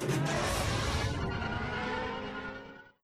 speeder_boost3.wav